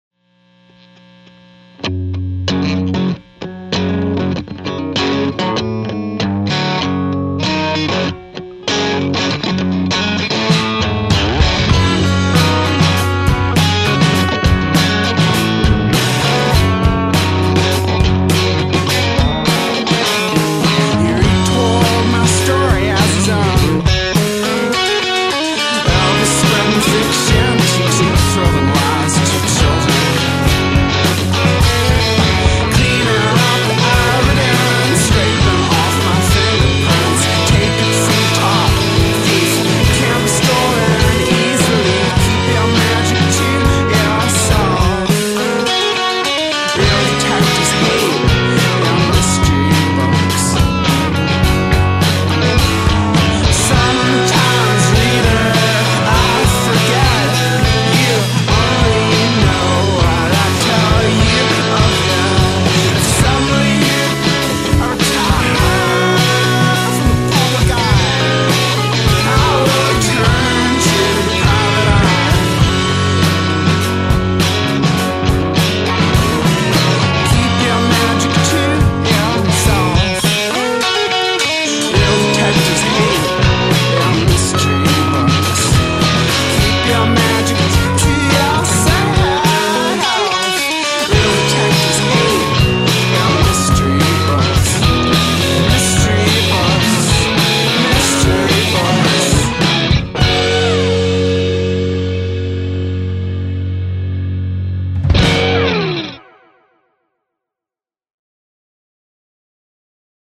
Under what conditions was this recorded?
recorded over the course of three days